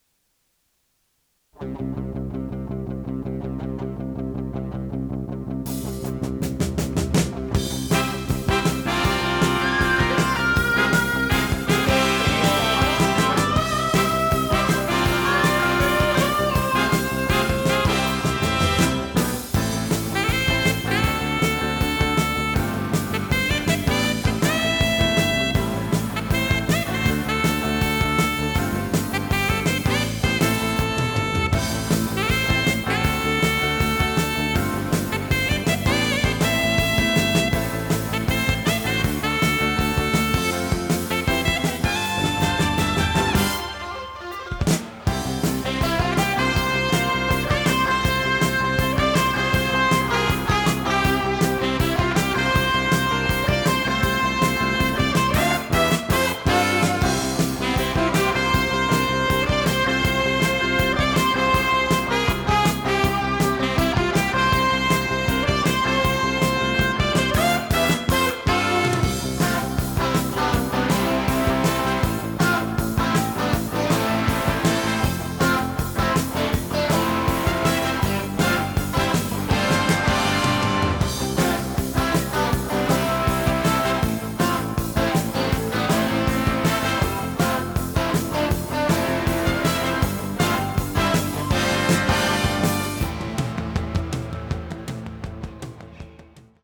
テープ：RTM
ノイズリダクションOFF
【フュージョン・ロック】96kHz-24bit 容量53.0MB